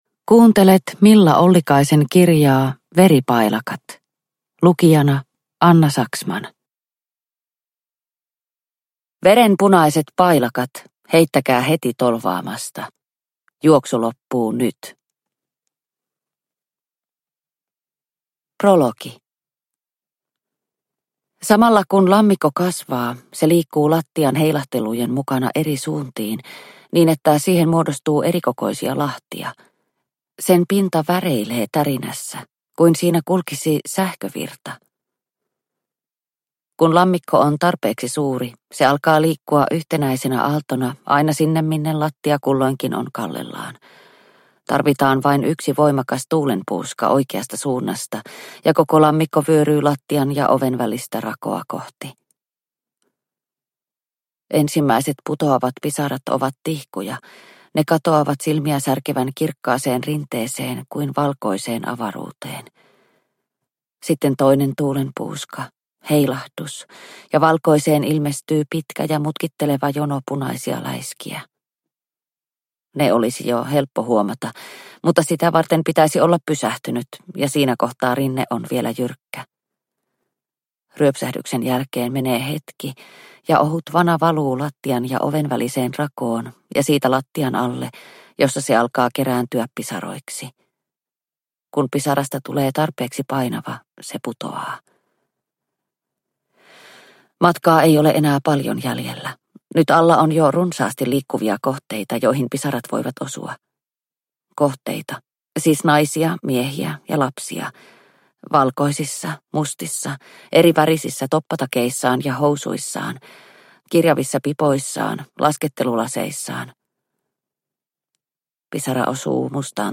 Veripailakat – Ljudbok – Laddas ner